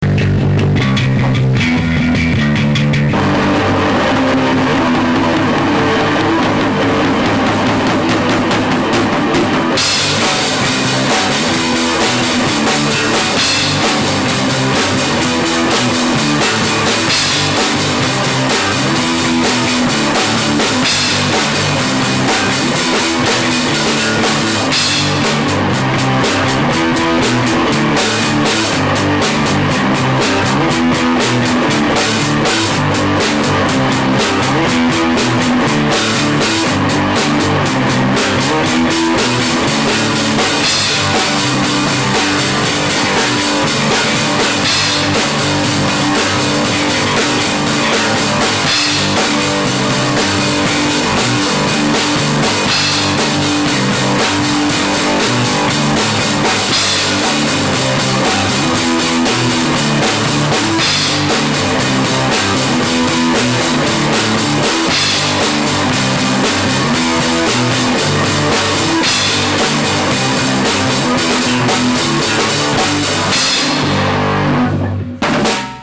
I don't know what kind of introduction would have been proper for our band section... we're a garage band from Palm Harbor. we play rock.
All the material available below was recorded using a Hi8 sony camcorder. the a double ended headphone cable was run to my mic input and sound recorder was used to record from the tape.
Improvised Jam #3